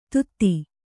♪ tutti